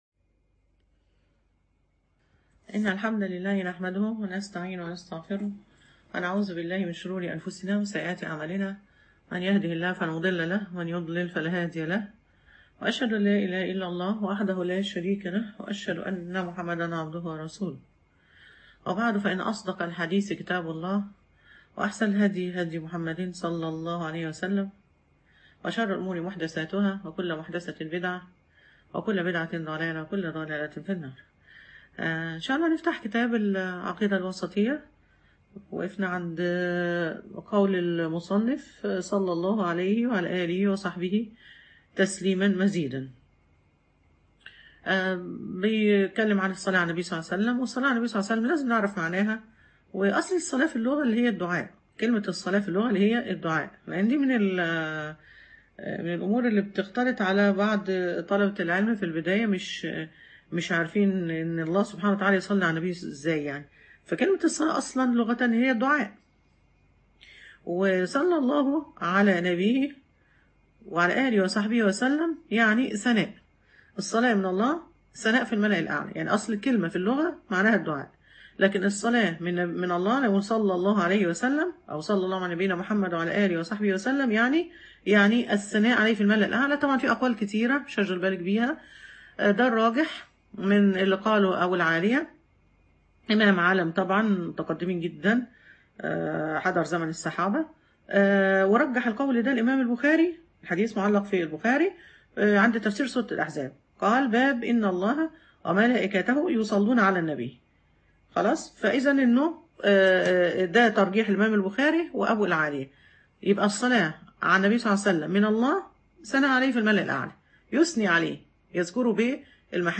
شرح العقيدة الواسطية_المحاضرة الثالثة
العقيدة_الواسطية_المحاضرة_الثالــثــة_أكاديمية_الفتيات.mp3